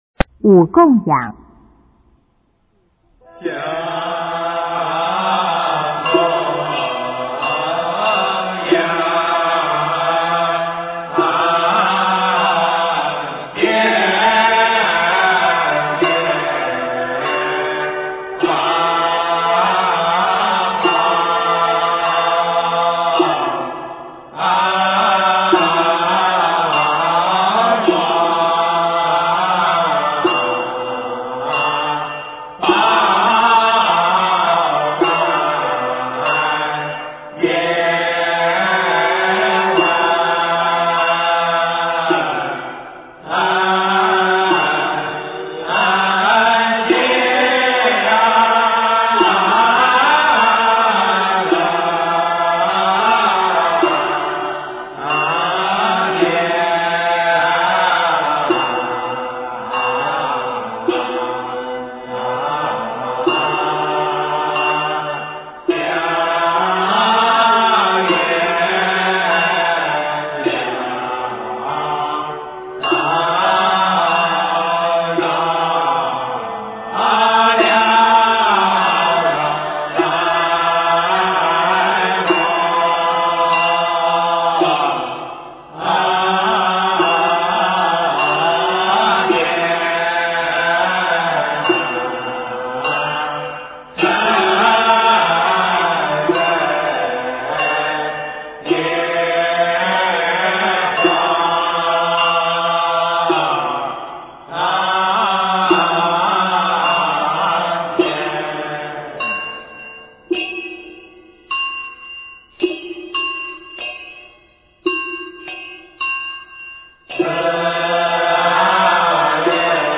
中国江南体育场馆中国有限公司 音乐 全真正韵 五供养